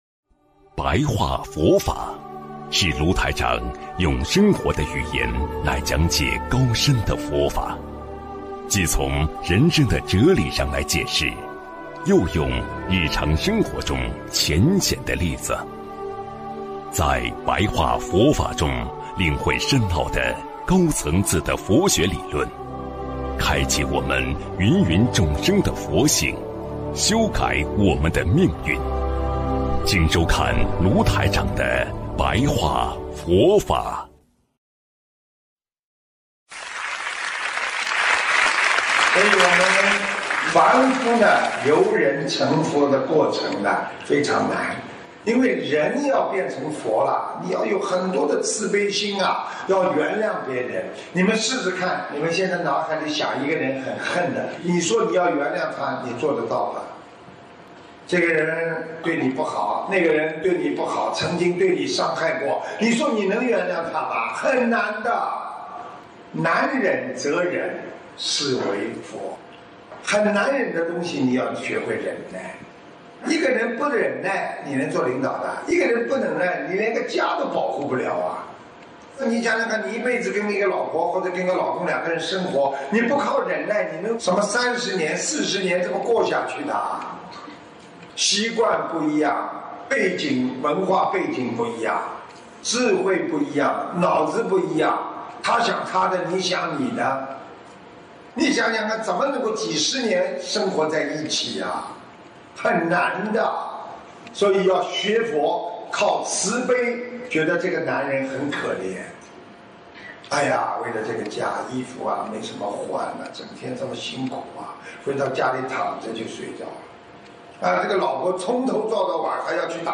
首页 >>佛法书籍 >> 广播讲座